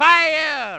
One of Wario's voice clips in Mario Kart 64